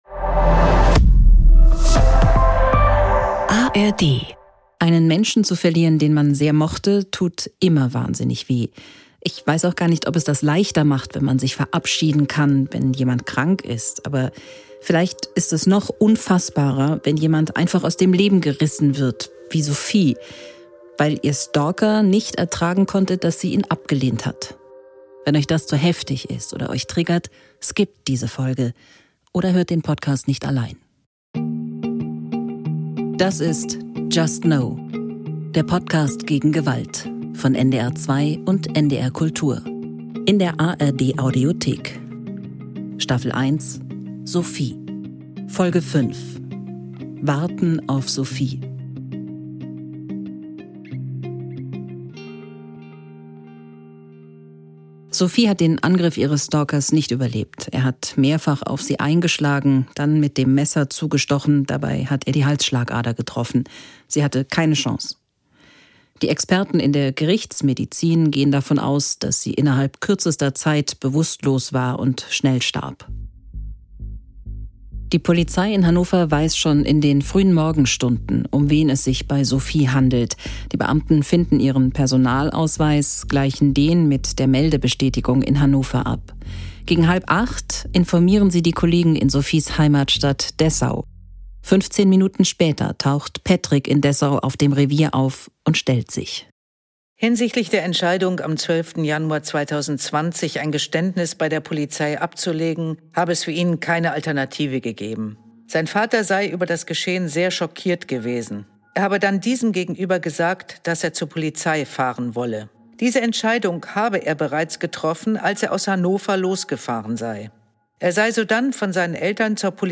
just no! ist ein True Crime-Podcast von NDR 2 und NDR Kultur von 2023.